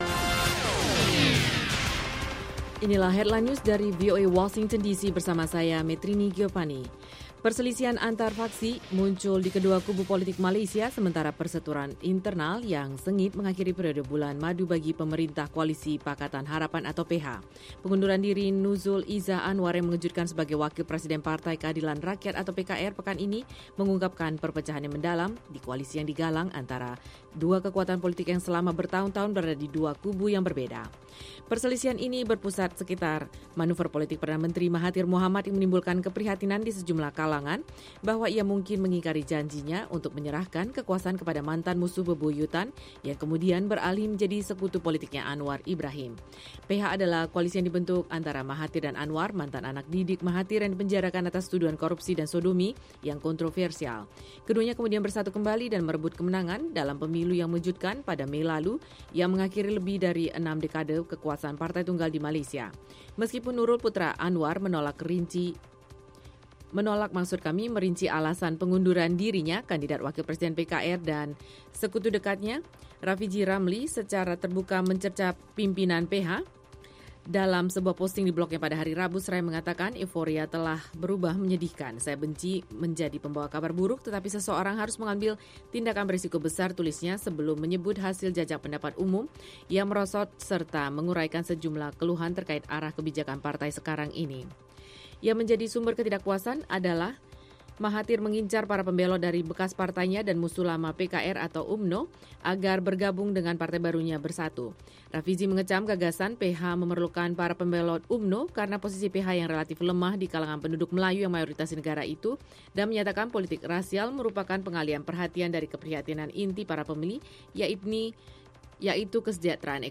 Simak berita terkini langsung dari Washington dalam Headline News, bersama para penyiar VOA yang setia menghadirkan perkembangan terakhir berita-berita internasional.